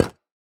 Minecraft Version Minecraft Version snapshot Latest Release | Latest Snapshot snapshot / assets / minecraft / sounds / block / decorated_pot / step1.ogg Compare With Compare With Latest Release | Latest Snapshot